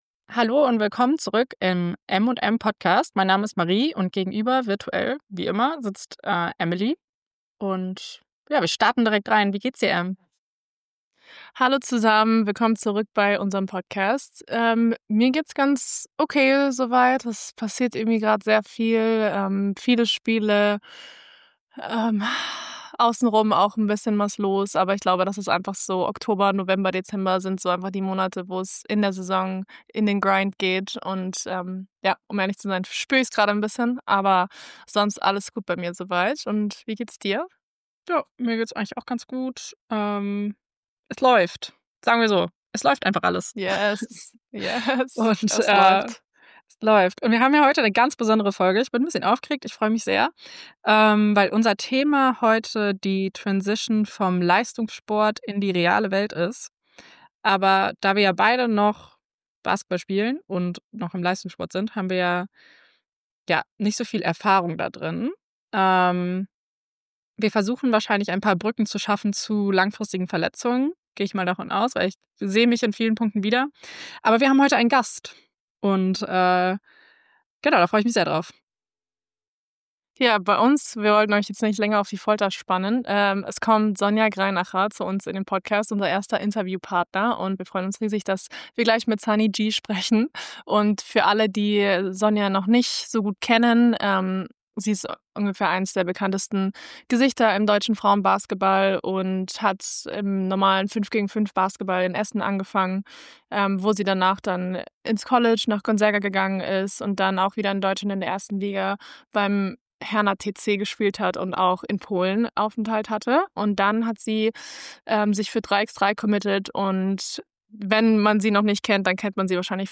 Sonja spricht nicht nur ehrlich über den Neuanfang, die Suche nach einer neuen Identität und darüber, was Erfolg heute für sie bedeutet, sie teilt mit uns auch mit was ihr dabei hilft mit den neuen Herausforderungen umzugehen. Ein offenes, inspirierendes Gespräch über Loslassen, Dankbarkeit und den Mut, sich neu zu erfinden.